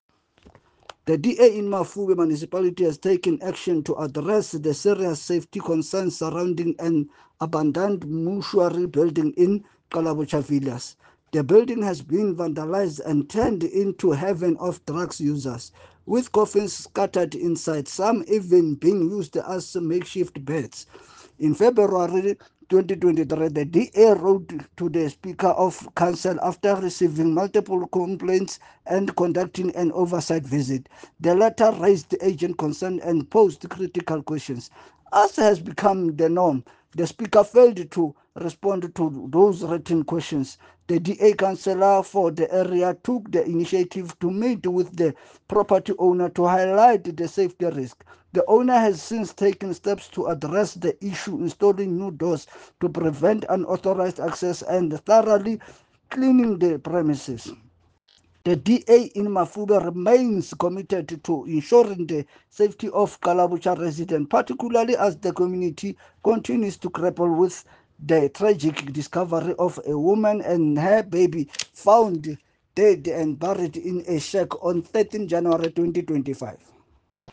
Sesotho soundbites by Cllr Fako Tsotetsi.